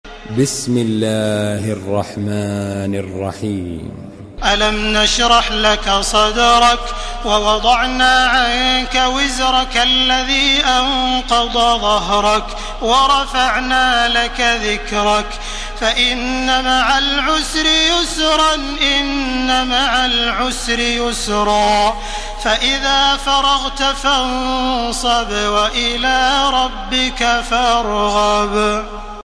Surah আশ-শারহ MP3 by Makkah Taraweeh 1431 in Hafs An Asim narration.
Murattal